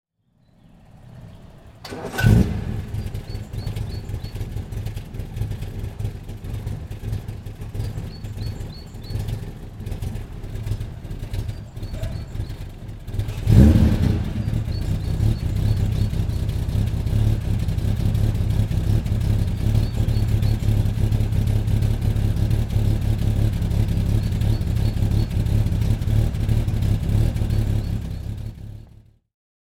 Lagonda 3 Litre Drophead Coupé (1953) - Starten und Leerlauf
Ton 1953